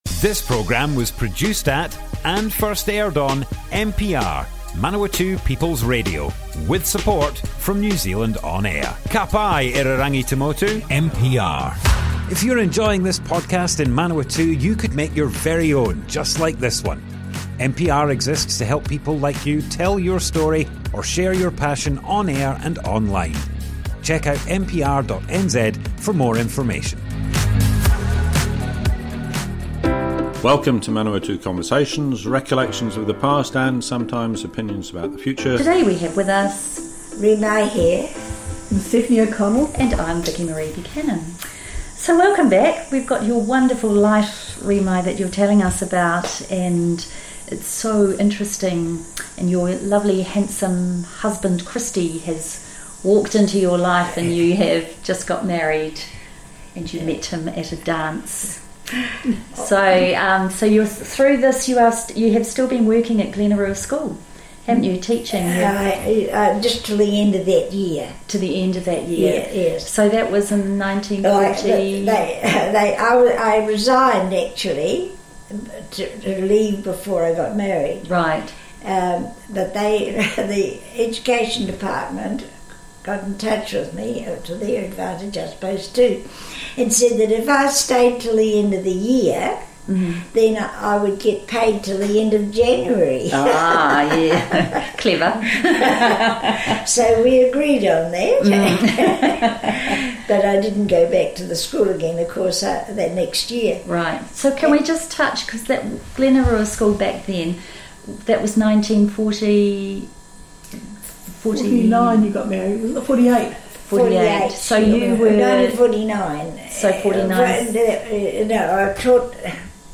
Manawatu Conversations More Info → Description Broadcast on Manawatu People's Radio, 18th April 2023.
oral history